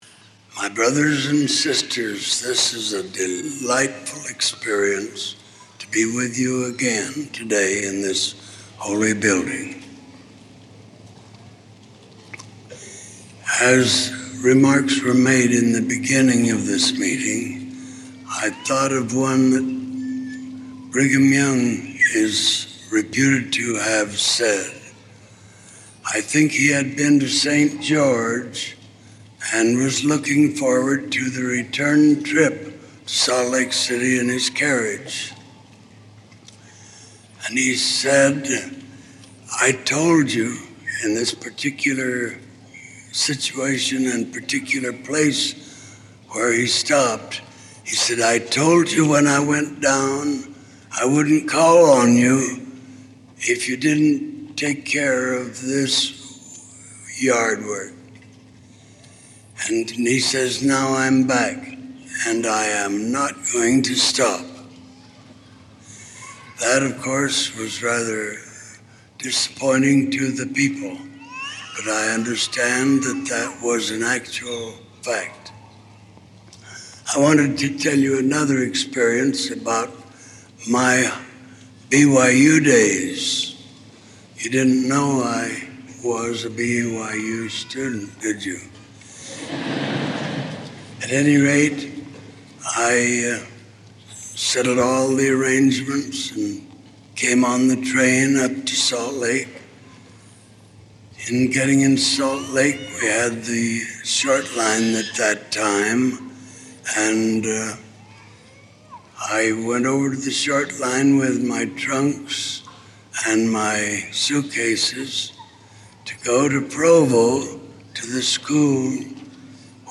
Audio recording of Acquiring Spiritual Literacy by Spencer W. Kimball
President of The Church of Jesus Christ of Latter-day Saints